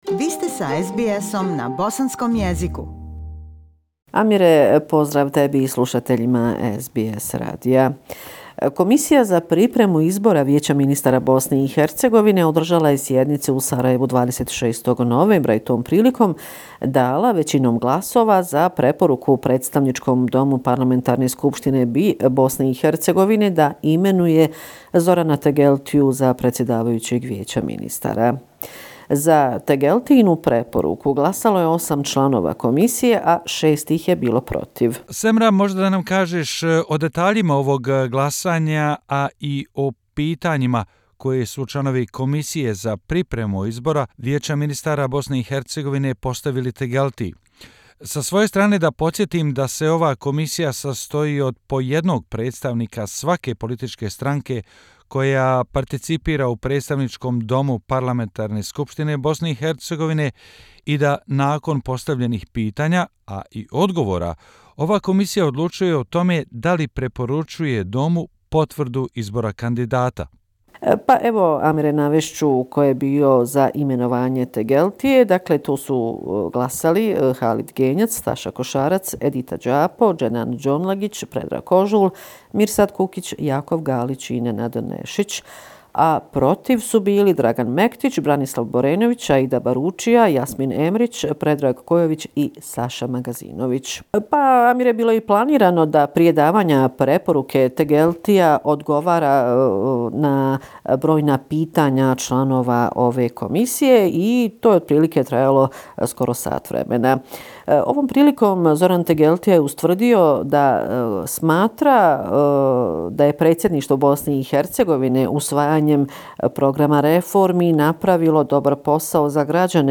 Bosnia and Herzegovina - affairs in the country for the last seven day, weekly report December 1, 2019